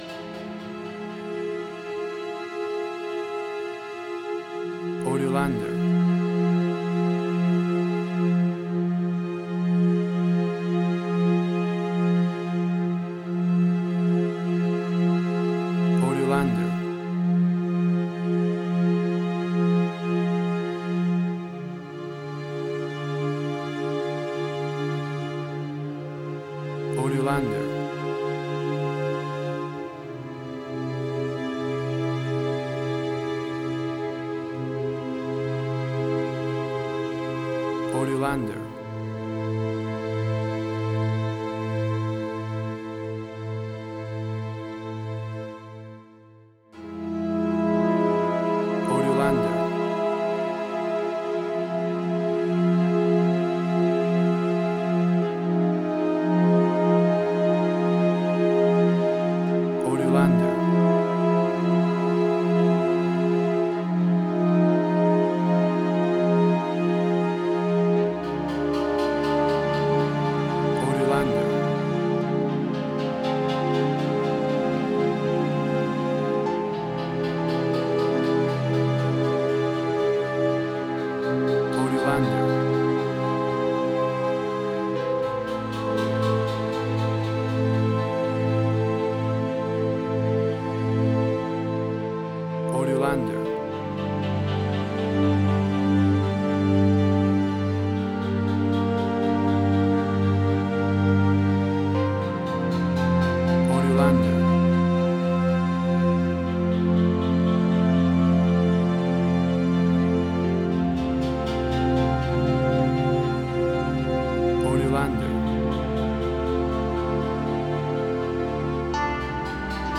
Modern Film Noir.